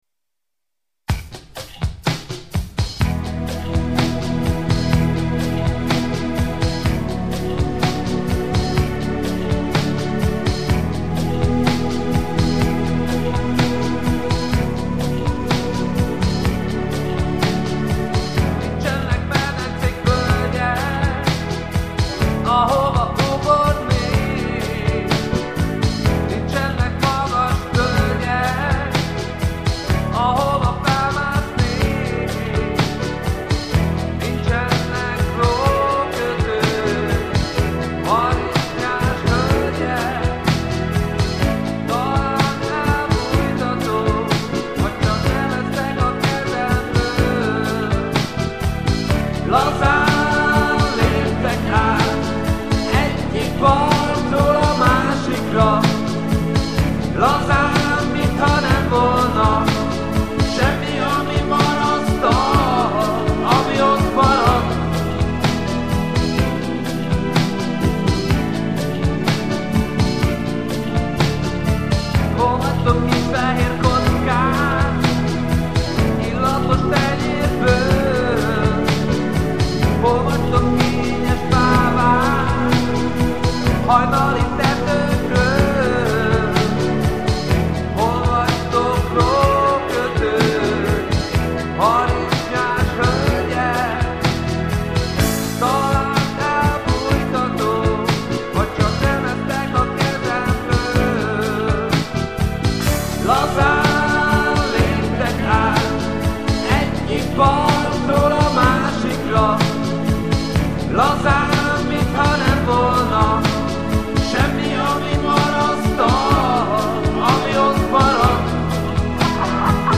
rádióbarát stúdiófelvétel